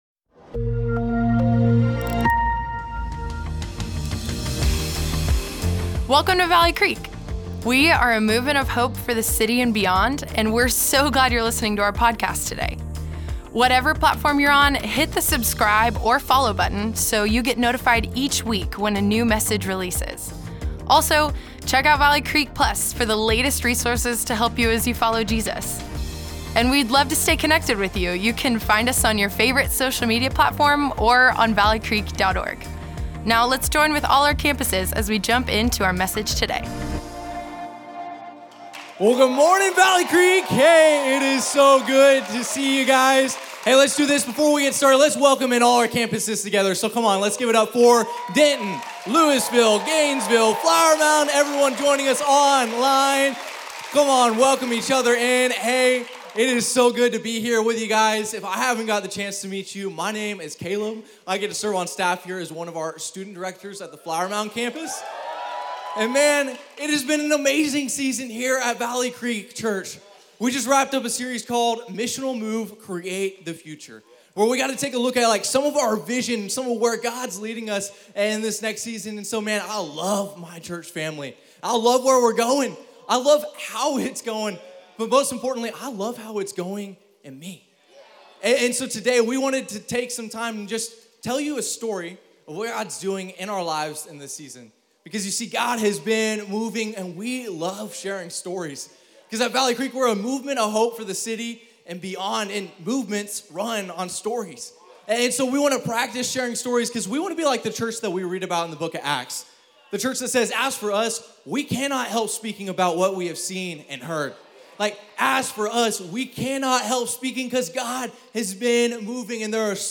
This weekend, three of our next gen communicators told us about what God is teaching them in this season.